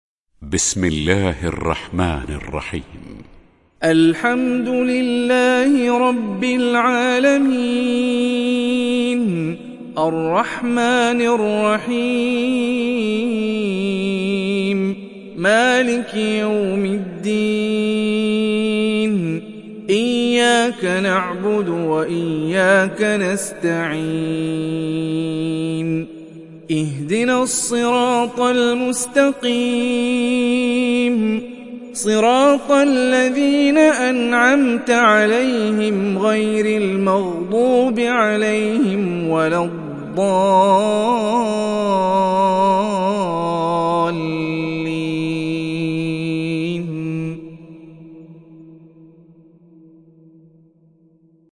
Surah Al Fatihah Download mp3 Hani Rifai Riwayat Hafs from Asim, Download Quran and listen mp3 full direct links